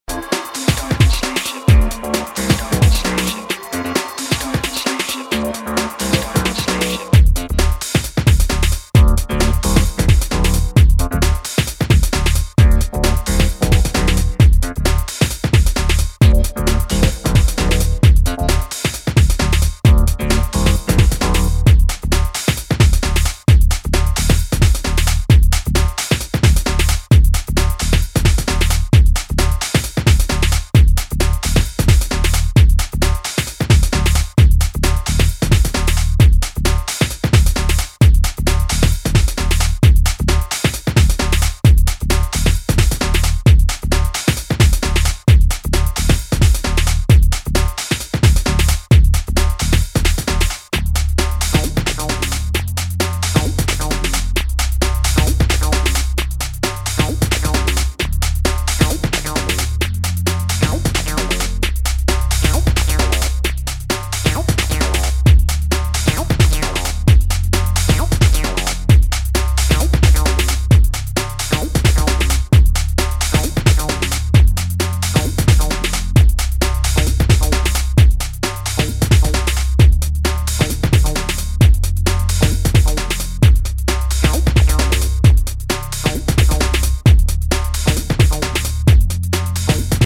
banging remixes